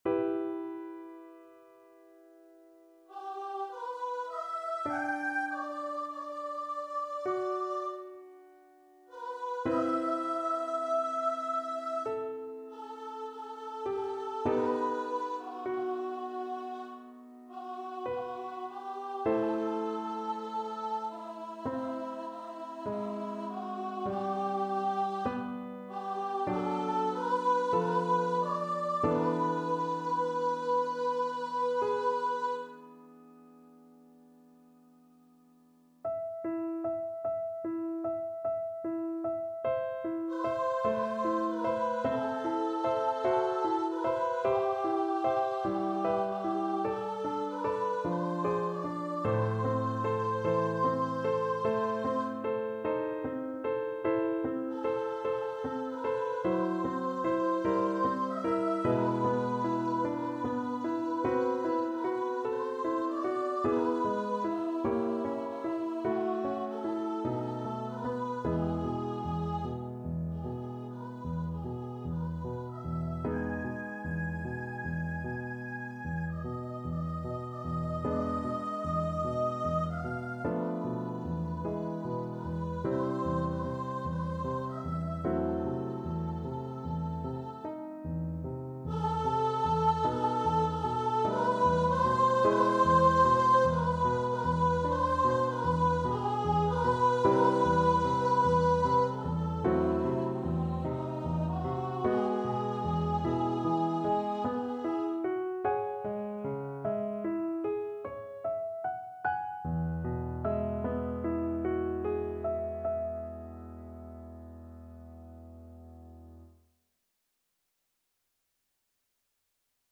4/4 (View more 4/4 Music)
~ = 100 Lento =50
G major (Sounding Pitch) (View more G major Music for Voice )
Voice  (View more Intermediate Voice Music)
Classical (View more Classical Voice Music)